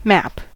map: Wikimedia Commons US English Pronunciations
En-us-map.WAV